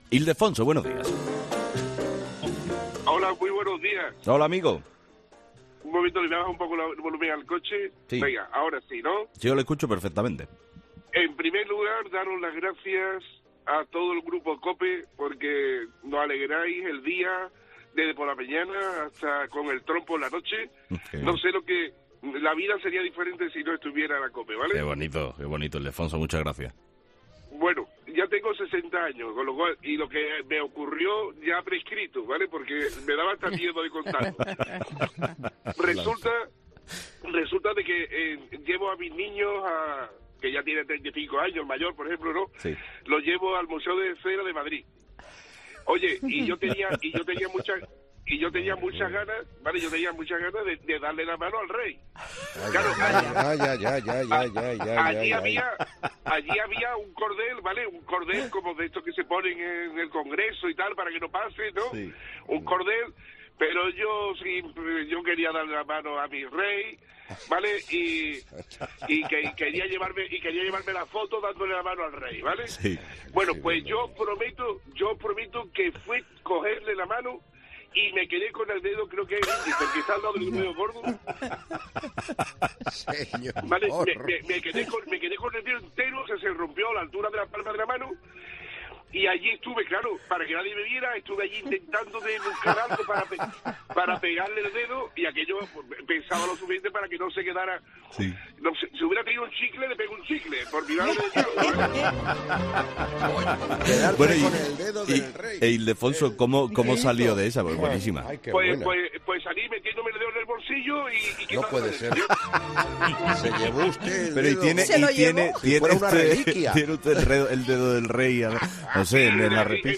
Los 'fósforos' de 'Herrera en COPE' hablan de su torpeza y las situaciones comprometidas que han vivido por su culpa